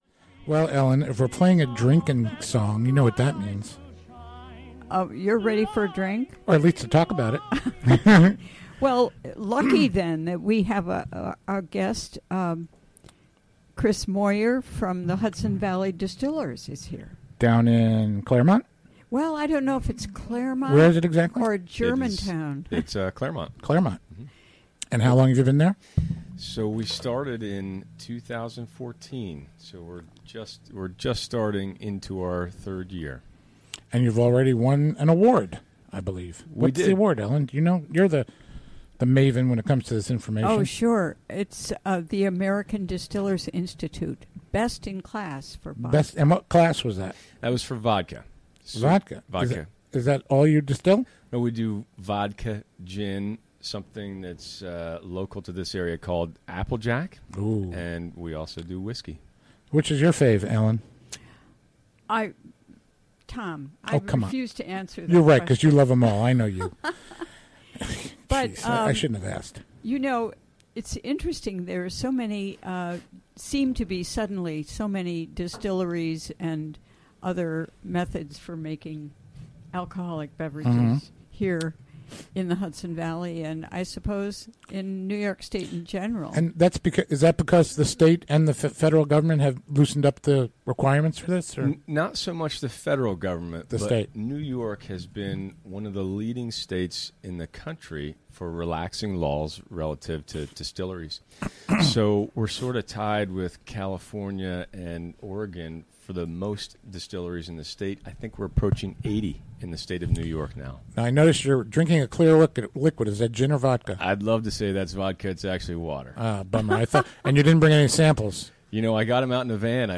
Interview from the WGXC Afternoon Show on Thursday, May 12.